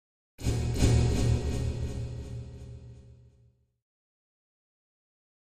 Drum Metallic Hit With Echo 2